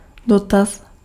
Ääntäminen
Ääntäminen Tuntematon aksentti: IPA: /dɔtas/ Haettu sana löytyi näillä lähdekielillä: tšekki Käännös Konteksti Ääninäyte Substantiivit 1. inquiry US 2. enquiry British UK 3. query tietojenkäsittely Suku: m .